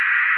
radio_random2.ogg